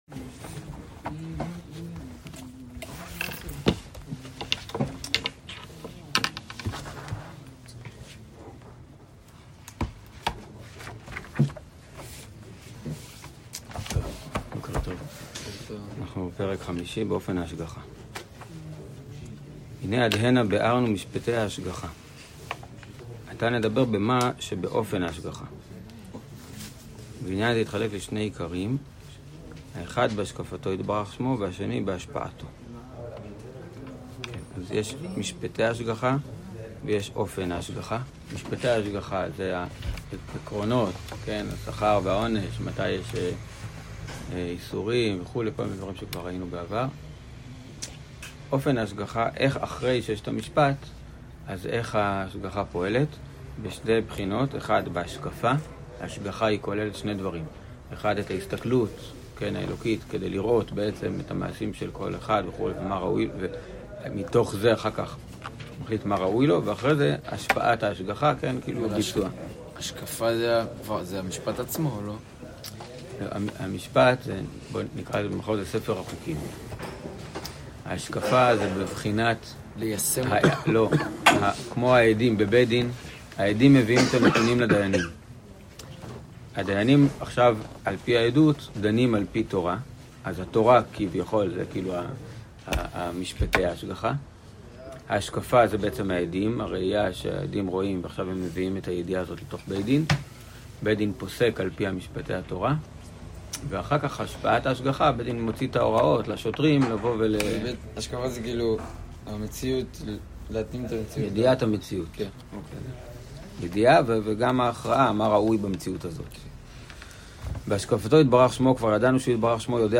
שיעור חלק ב'